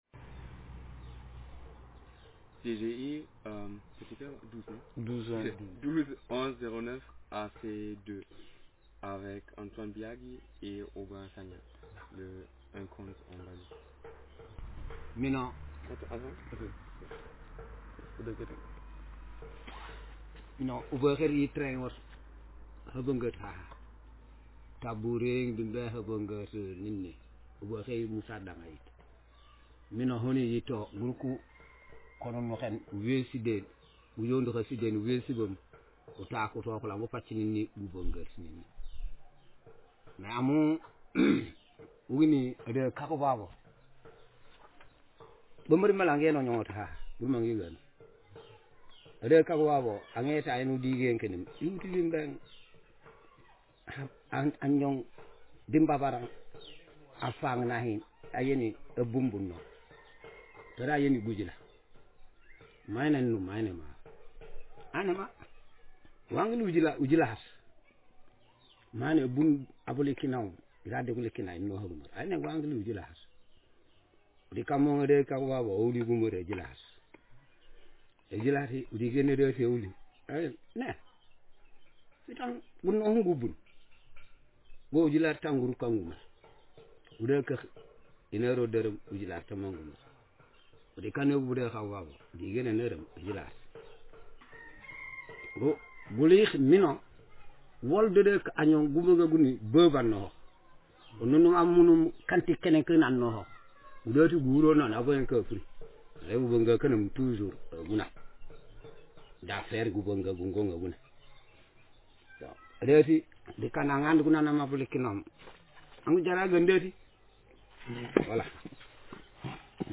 Speaker sexm/m
Text genreconversation